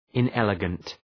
{ın’eləgənt}
inelegant.mp3